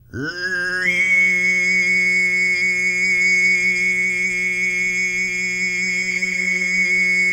TUV1 DRONE10.wav